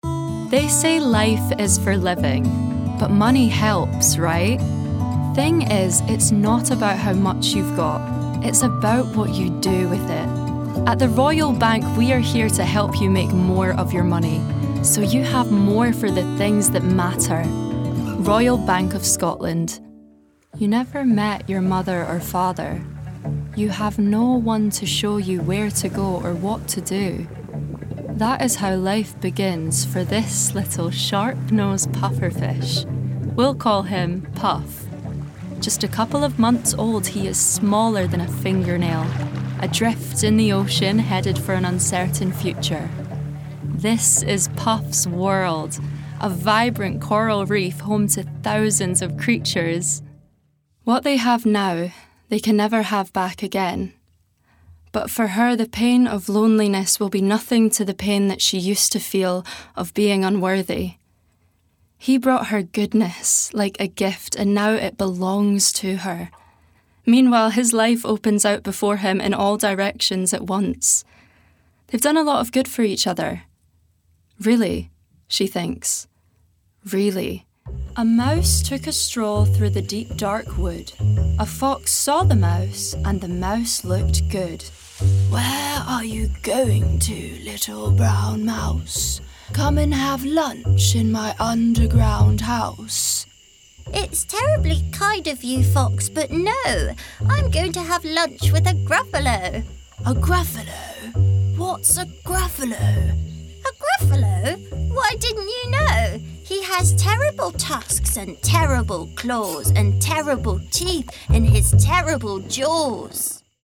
Native voice:
Dunblane
Voicereel:
BRITISH ISLES: Contemporary RP, Heightened RP, Yorkshire, Essex, Edinburgh
GLOBAL: Standard-American, American-Southern States, New York, Russian, Australian